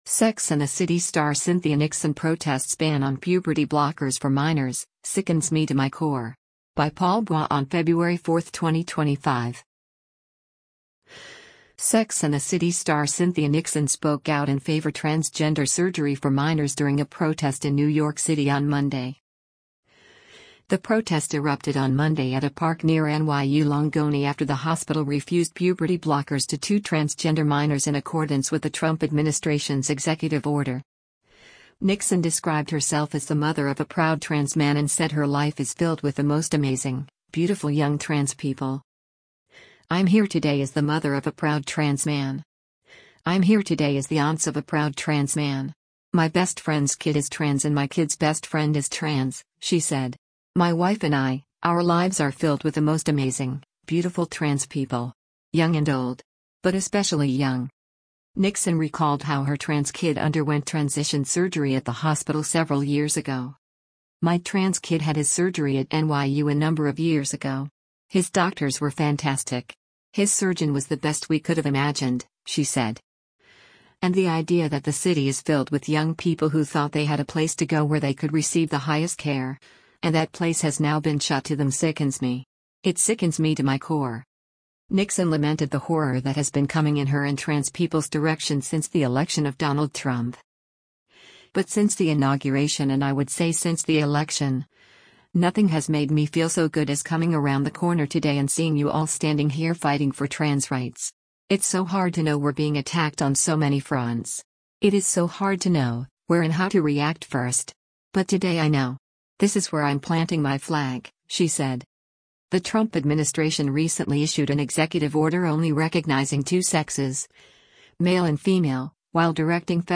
Sex and the City star Cynthia Nixon spoke out in favor transgender surgery for minors during a protest in New York City on Monday.
The protest erupted on Monday at a park near NYU Langone after the hospital refused puberty blockers to two transgender minors in accordance with the Trump administration’s executive order.